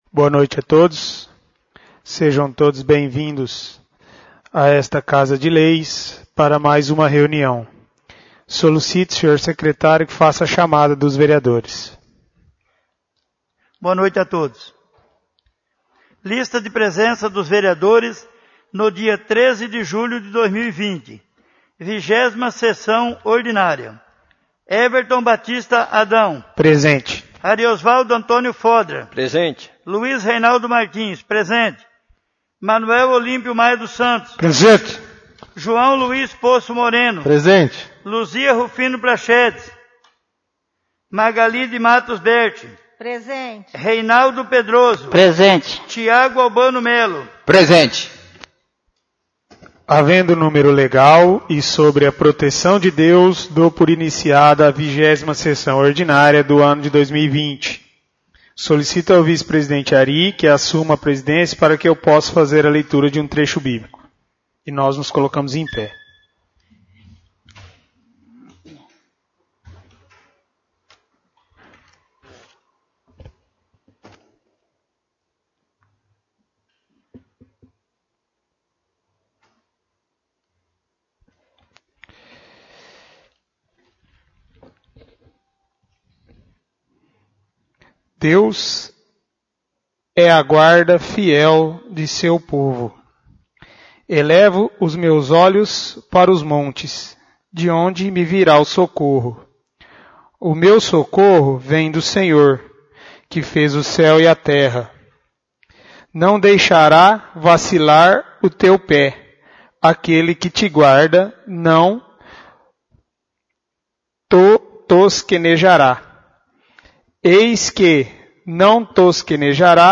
20° Sessão Ordinára — CÂMARA MUNICIPAL